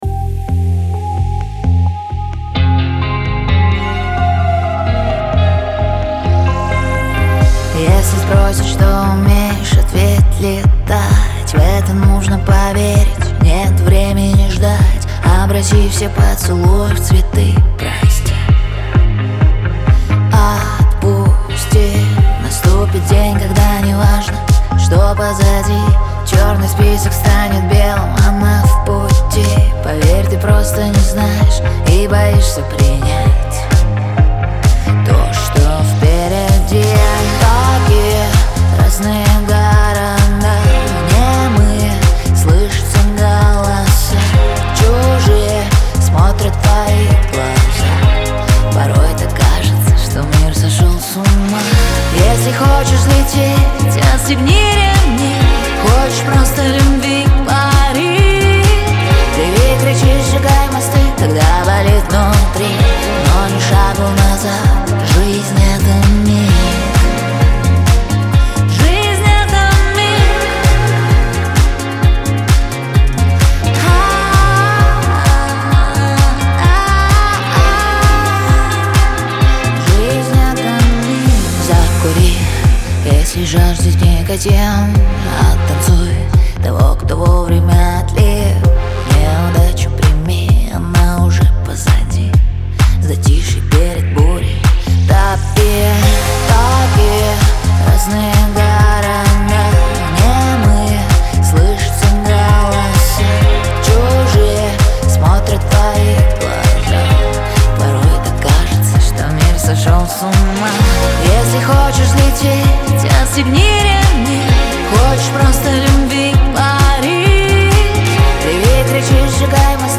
Лирика
диско , грусть